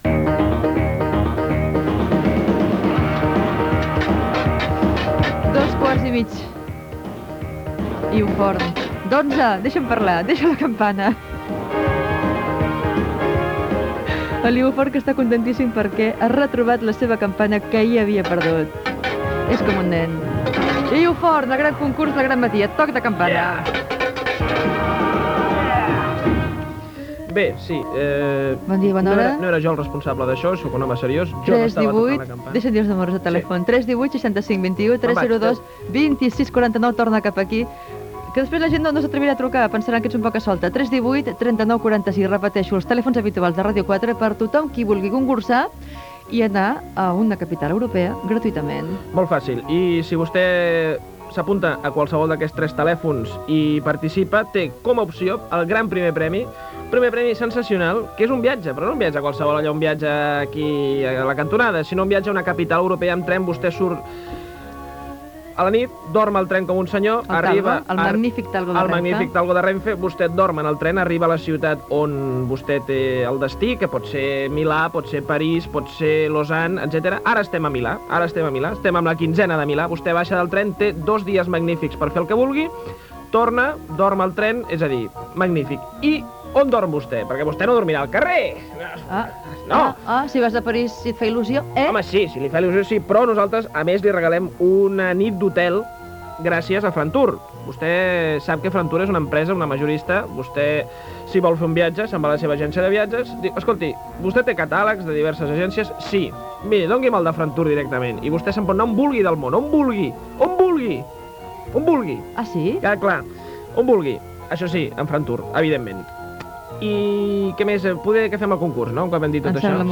Concurs amb trucades telefòniques amb toc humorístic. Presentació, explicació concurs i trucada que respon les preguntes.
Info-entreteniment